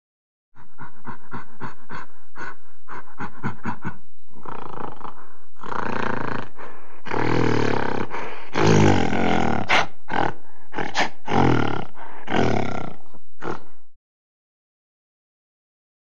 Gorilla Grunt. Series Of Short Grunts Followed By Long Growls. Close Perspective.